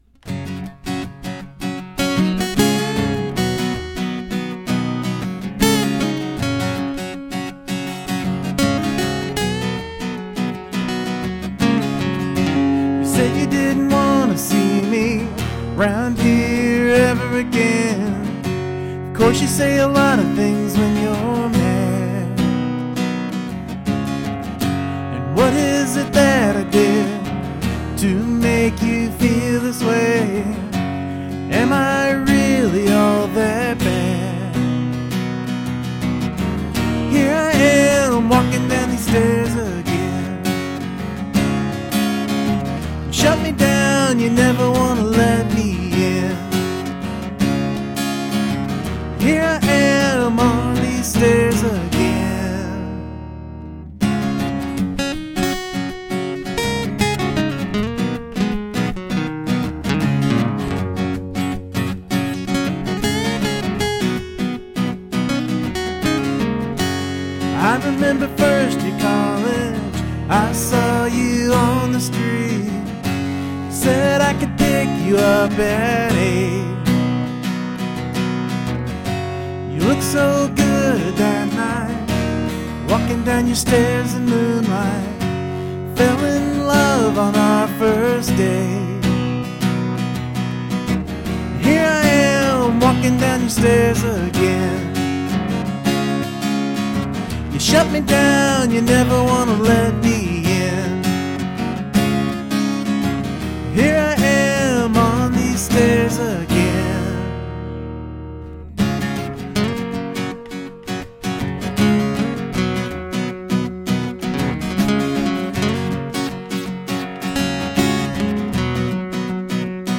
Clean, direct and wonderful.
The simple extra guitar line works really well.